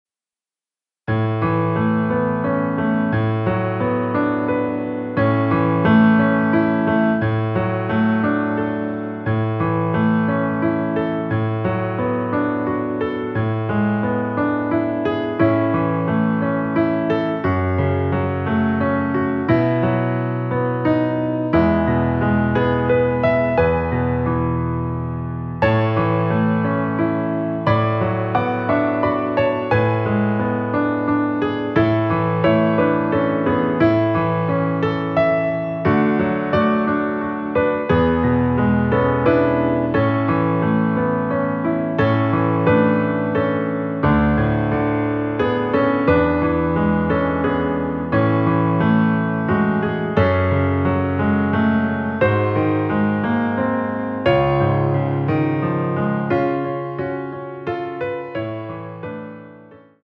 *악기가 피아노로만 제작된 원키 피아노 버젼입니다.
첫음이 도# 으로 시작 됩니다.
앞부분30초, 뒷부분30초씩 편집해서 올려 드리고 있습니다.
중간에 음이 끈어지고 다시 나오는 이유는